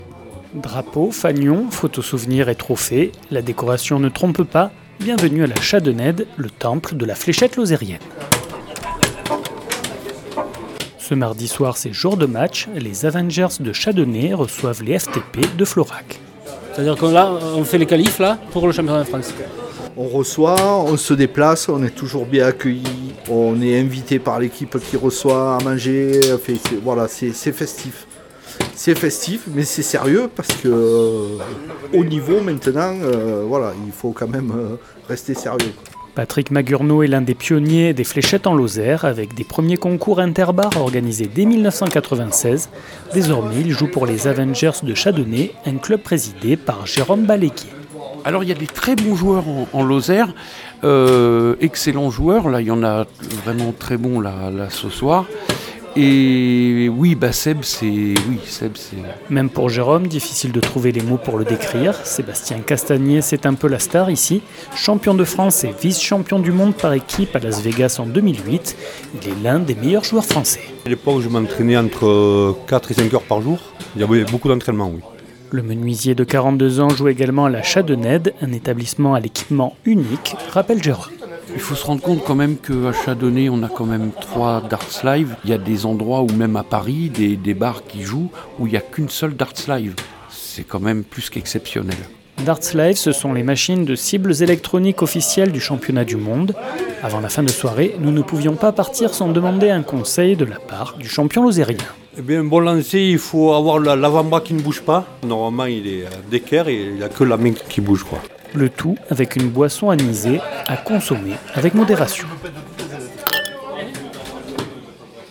Reportage au bar restaurant La Chadenede pour aller à la rencontre de ces champions !
Reportage